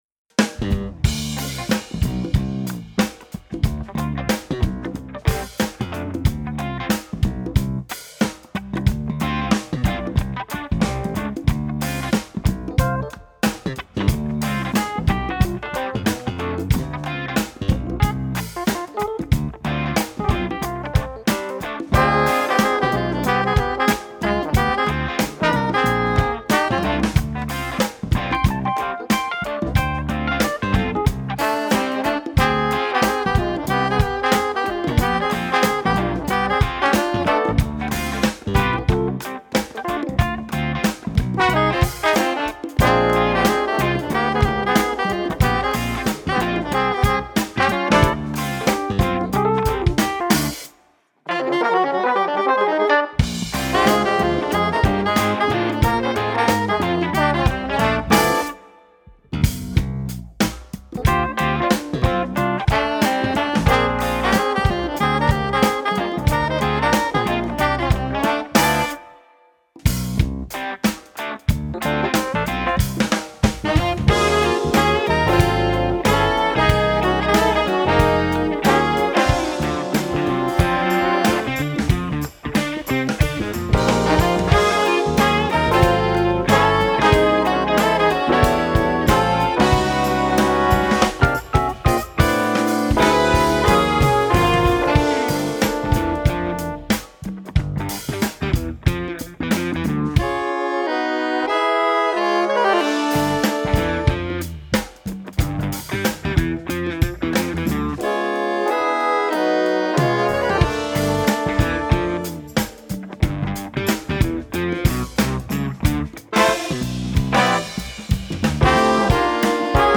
jazzpianist og jazzmusiker